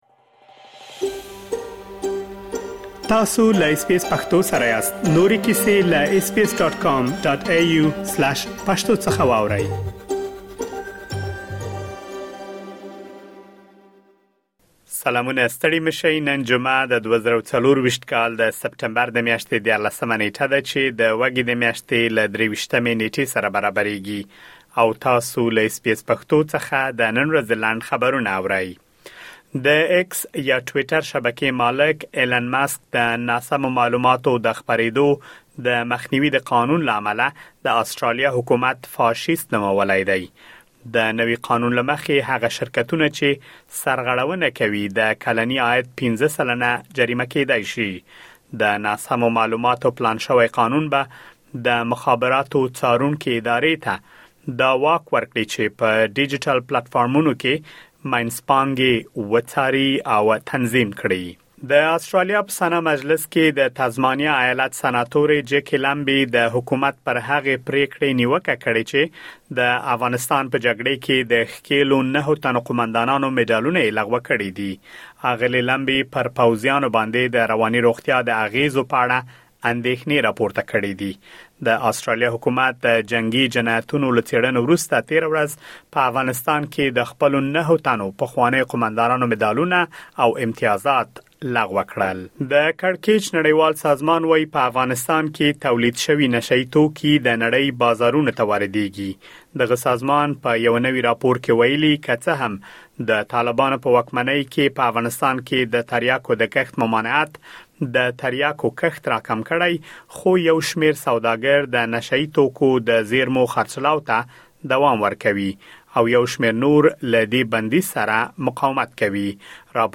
د اس بي اس پښتو د نن ورځې لنډ خبرونه|۱۳ سپټمبر ۲۰۲۴